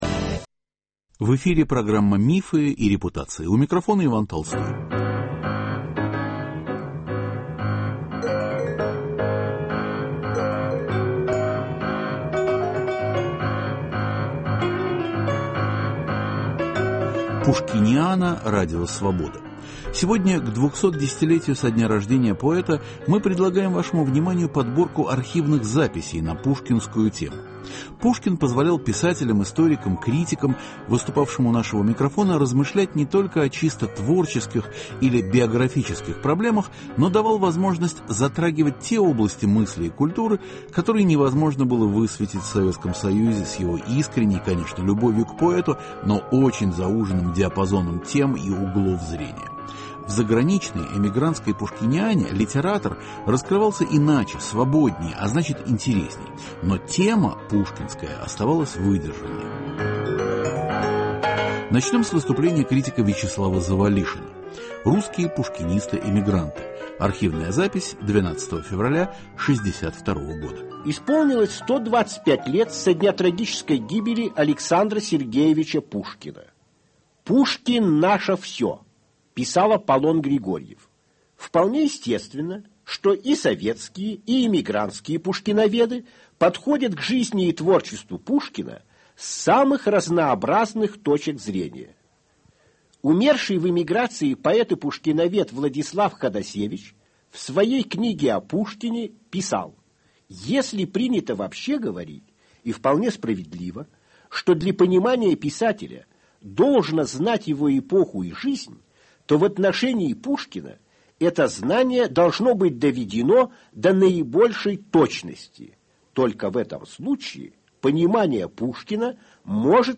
К 210-летию со дня рождения поэта мы предлагаем вашему вниманию подборку архивных записей на пушкинскую тему.